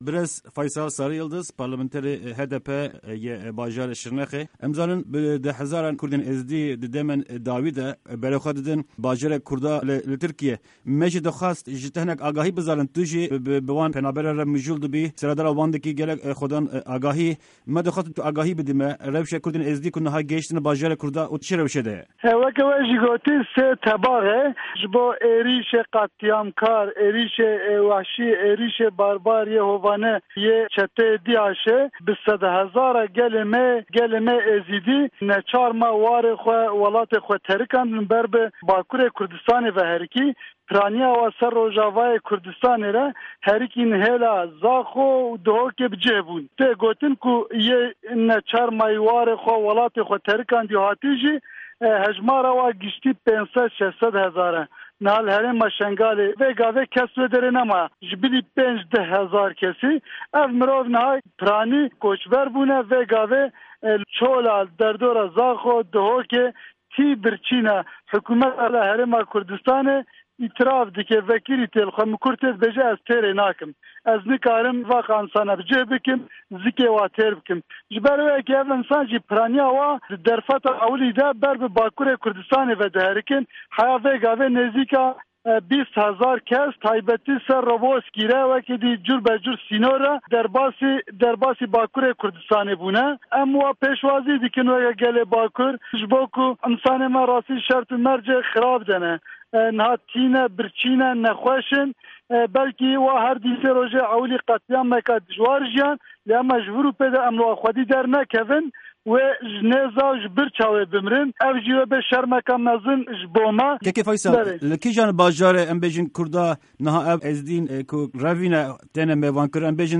Parlamentarê Şirnexê yê Partîya Demokratîk ya Gelan (HDP) Faysal Sariyildiz, di hevpeyvîna Dengê Amerîka de agahîyên girîng li ser wan penaberan dike.